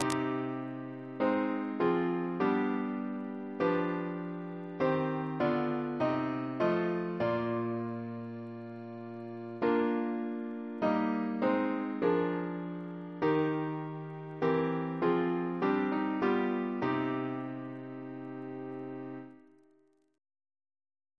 CCP: Chant sampler
Double chant in C minor Composer: Frederick W. Wadely (1882-1970) Reference psalters: ACB: 203